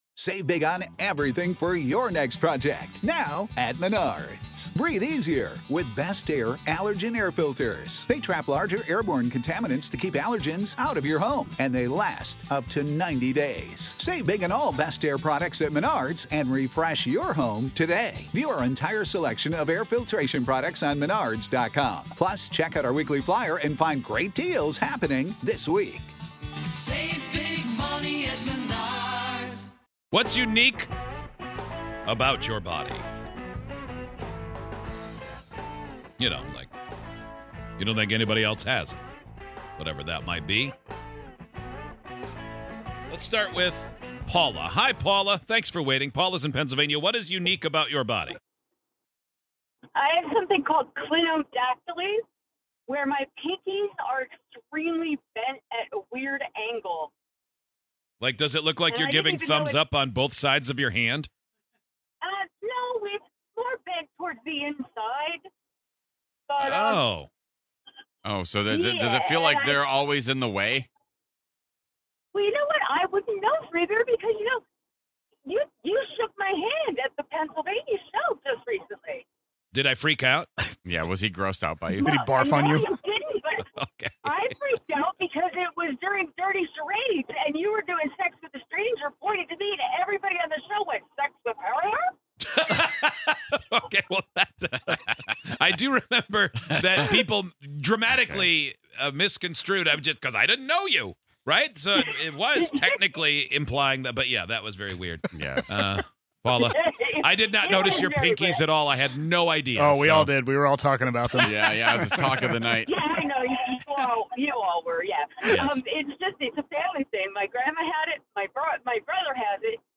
Their bodies either can do or have done things that most people, if anyone, can't! While one caller grew an additional ovary, another never has to experience sinus infections!